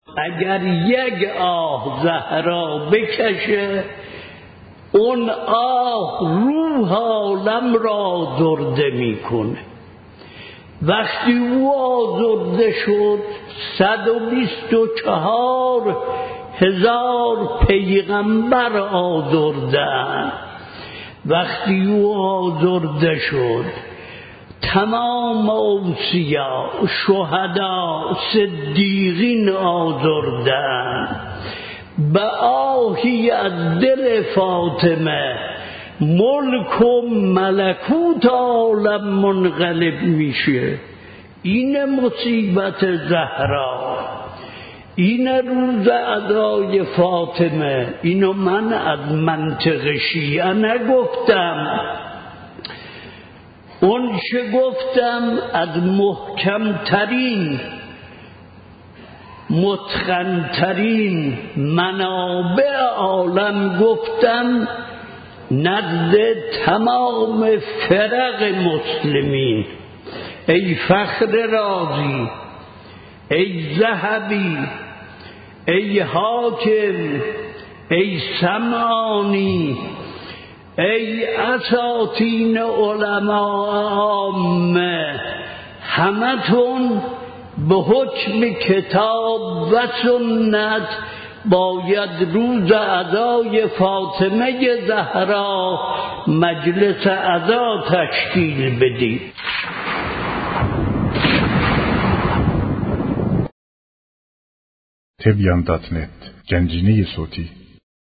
به مناسبت ایام فاطمیه فال صوتی سخنرانی مرجع عالی قدر، شیخ حسین وحید خراسانی، با هدف بیان بندگی و فضائل حضرت فاطمه زهرا (س) تقدیم دوستداران می شود.